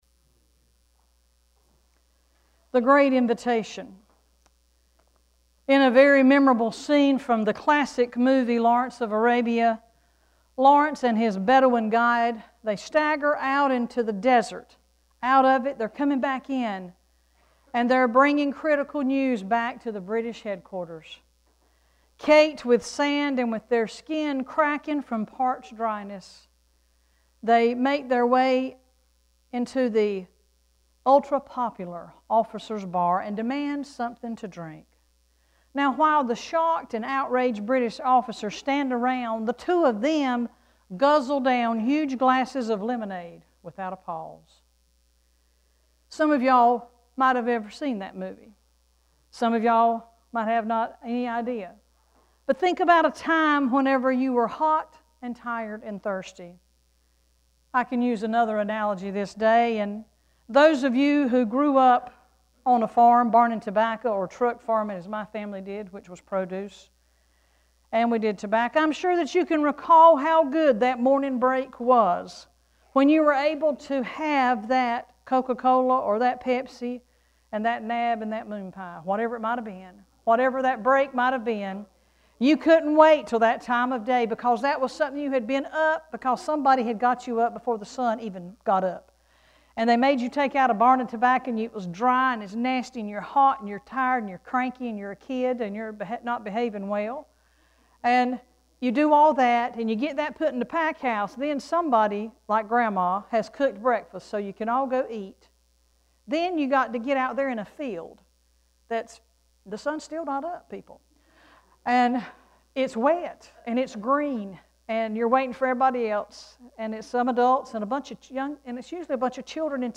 Worship Service 2-28-16: “The Great Invitation”
2-28-sermon.mp3